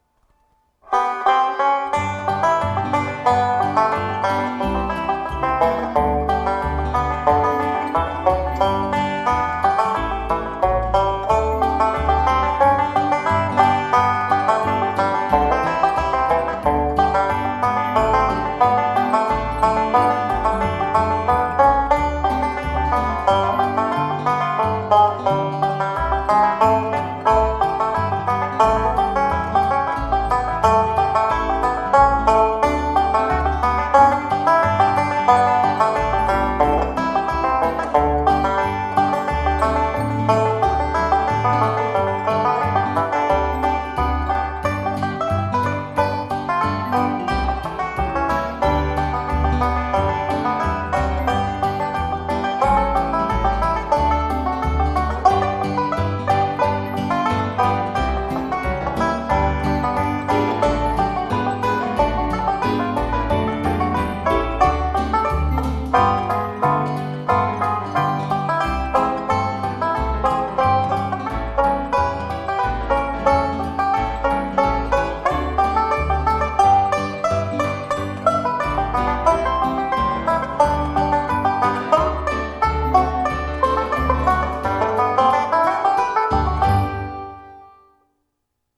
5-string banjo